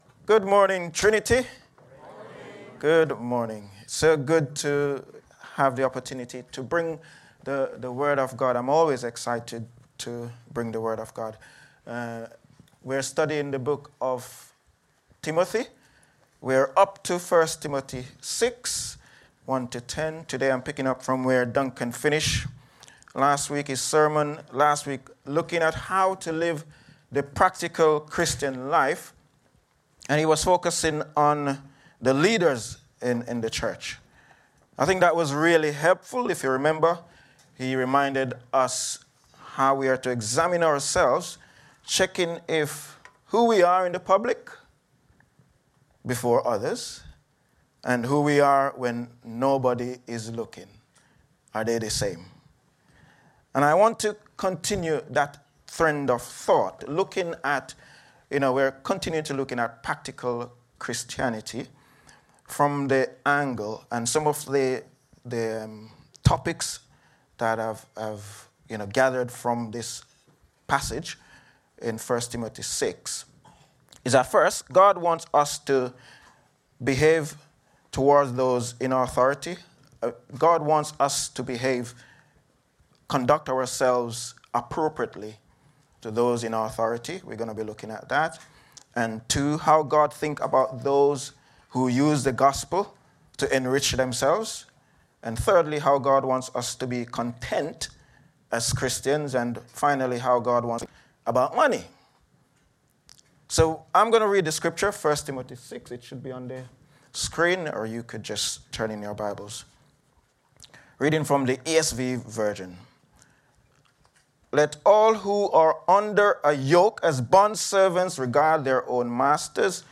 Download Money | Sermons at Trinity Church